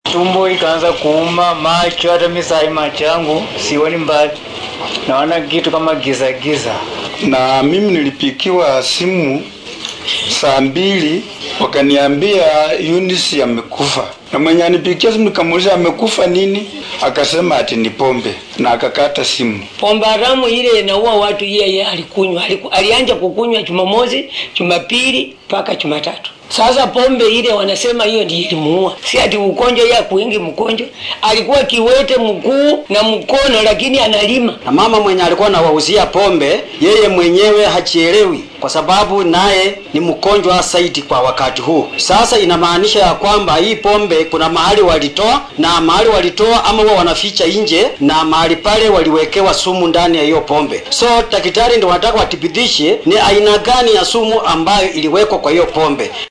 Qaar ka mid ehellada dadkii ku nafwaayay cabidda Khamriga ayaa la hadlay warbaahinta.